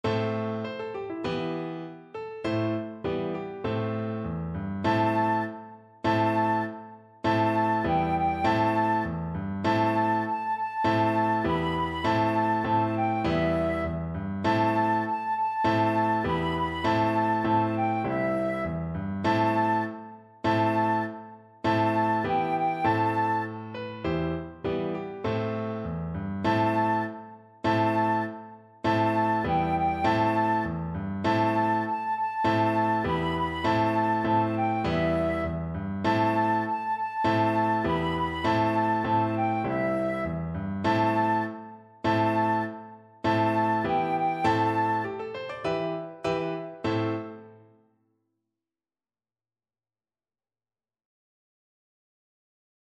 Flute
Traditional Music of unknown author.
A minor (Sounding Pitch) (View more A minor Music for Flute )
Firmly =c.100